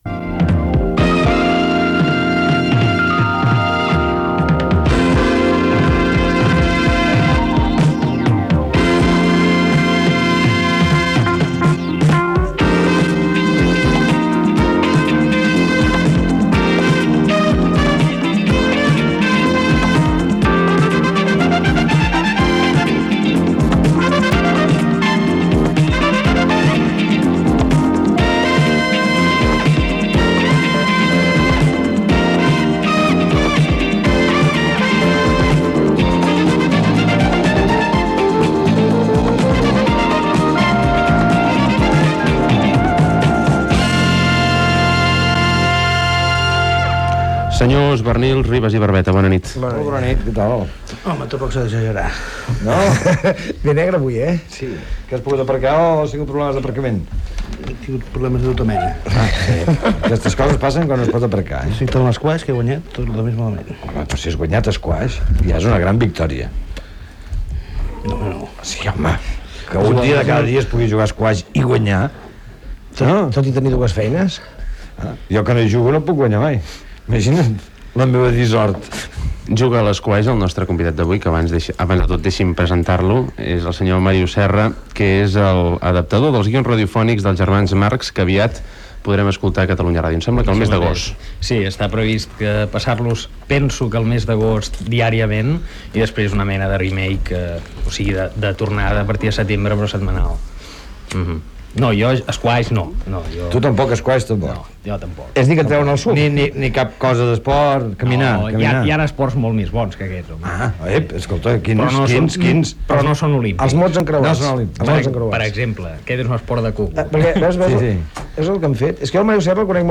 La tertúlia
Info-entreteniment